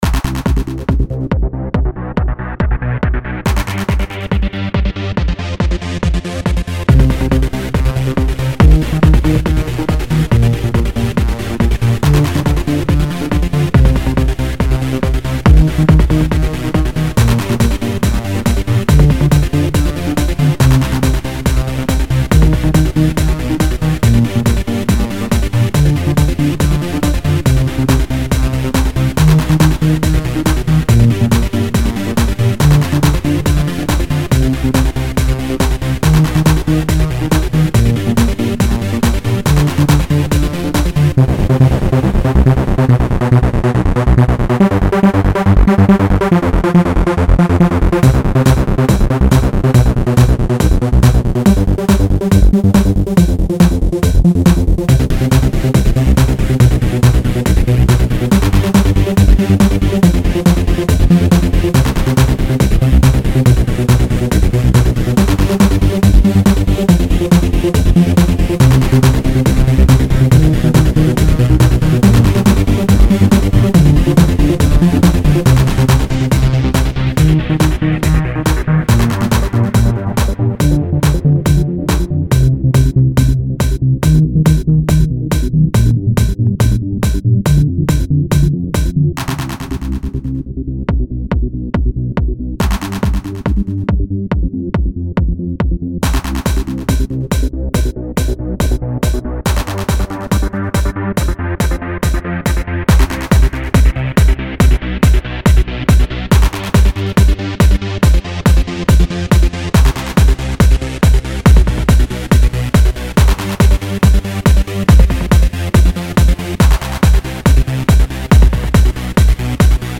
Not that repetetive as other techno songs and done with some nice and smooth sounds which don't go on your nerves, so it's nice to listen to it.
It's made well, the sounds are bearable.